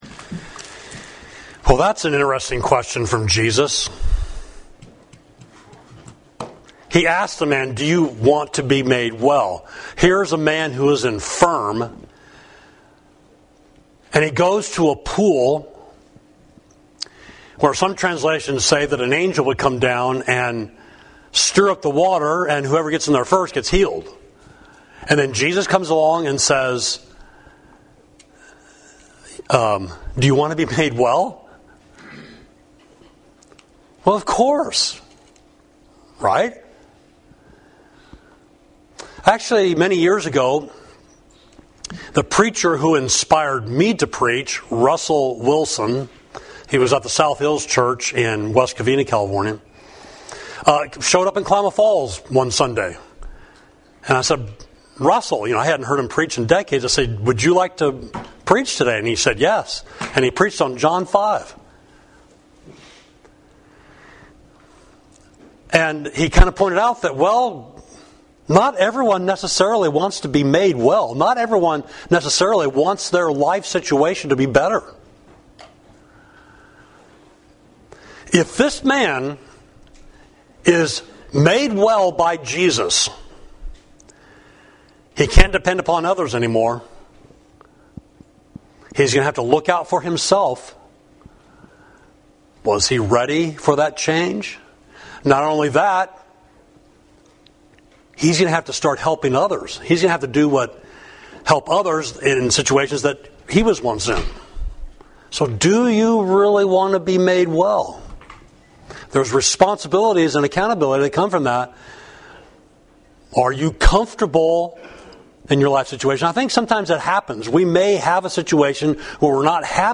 Sermon: Prayer and Salvation, Luke 18 – Savage Street Church of Christ